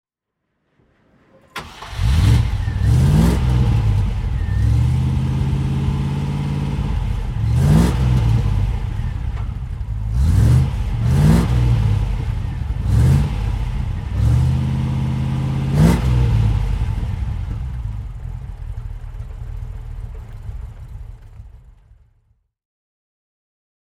Range Rover (1974) - Starten und Leerlauf
Range_Rover_1974.mp3